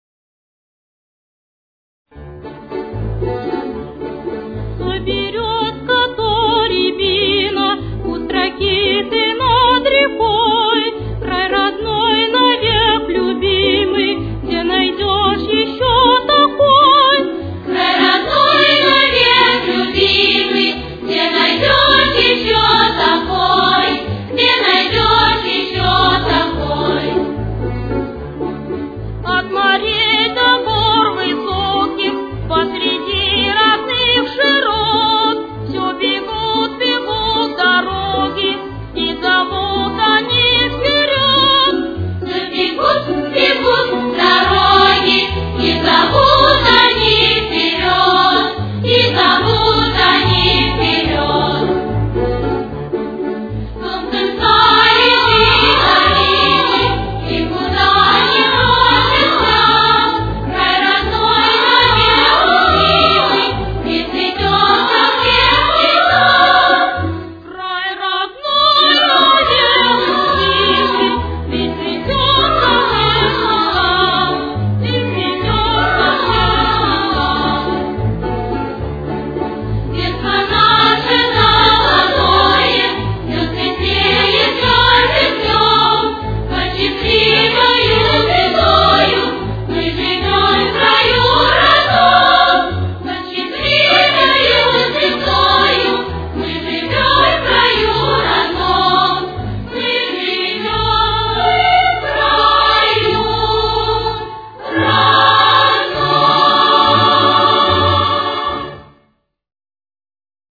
Темп: 225.